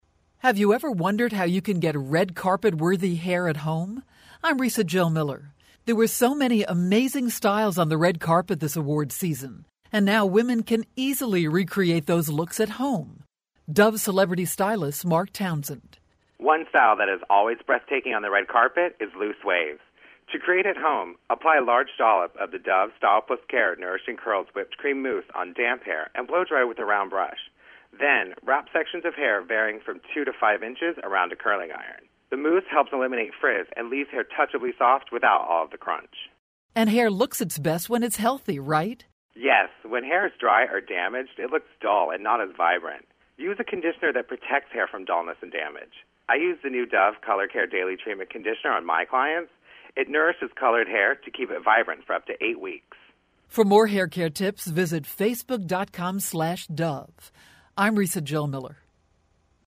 February 26, 2013Posted in: Audio News Release